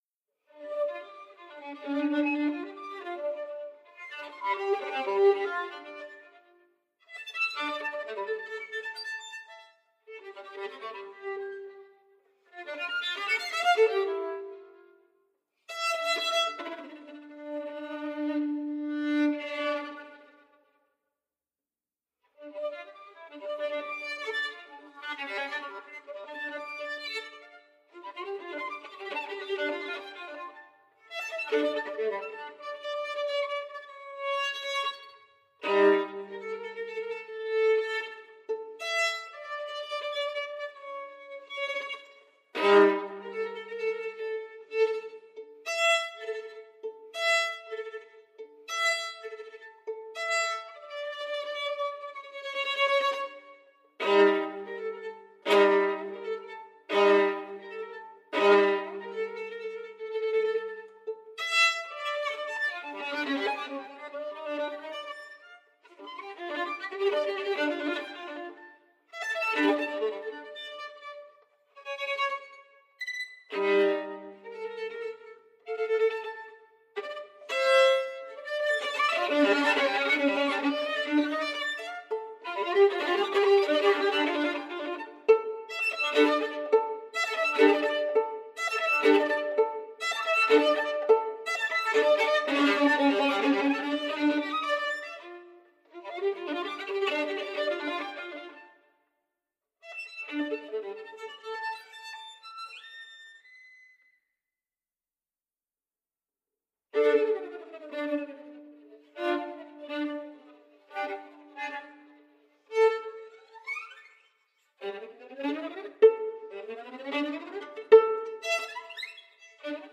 Finale Lesung München Kammerspiele Kammer 3, 13.
Geige